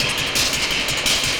RI_DelayStack_170-04.wav